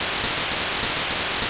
Rain.wav